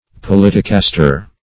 politicaster - definition of politicaster - synonyms, pronunciation, spelling from Free Dictionary
Search Result for " politicaster" : The Collaborative International Dictionary of English v.0.48: Politicaster \Po*lit"i*cas`ter\, n. [Cf. It. politicastro.] A petty politician; a pretender in politics.